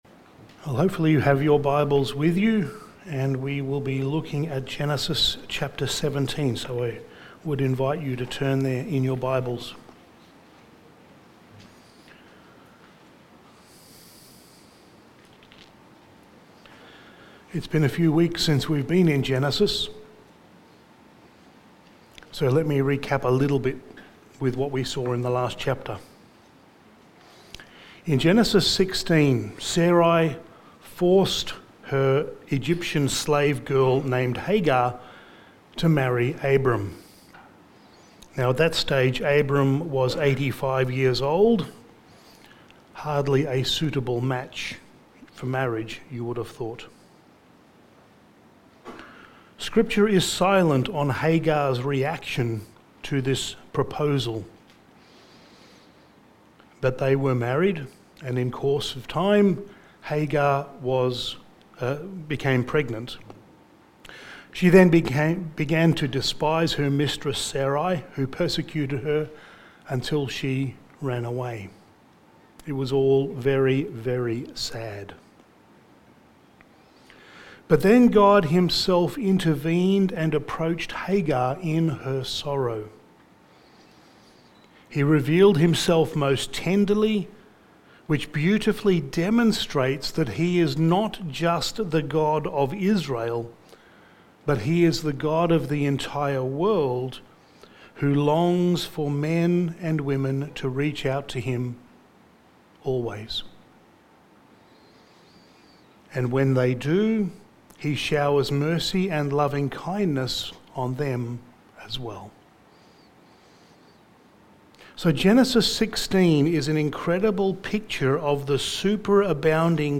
Sermon
Passage: Genesis 17:1-27 Service Type: Sunday Morning Sermon